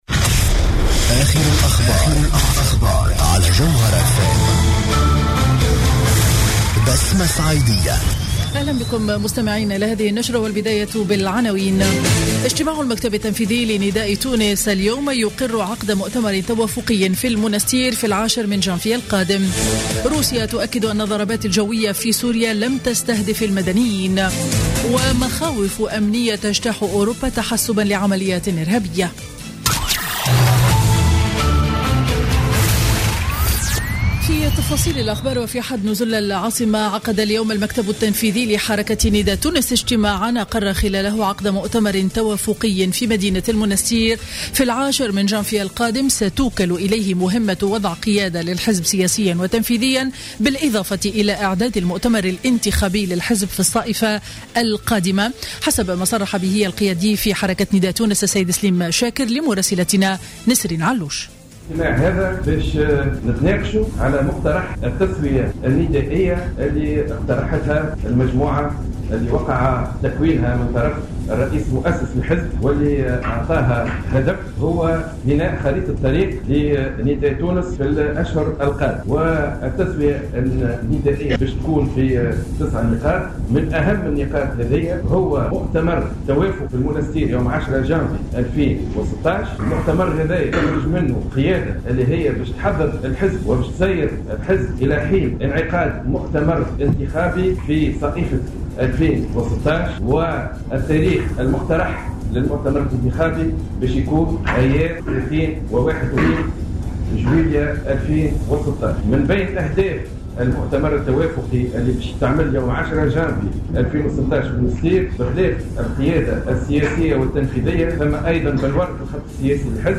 نشرة أخبار منتصف النهار ليوم الأحد 27 ديسمبر 2015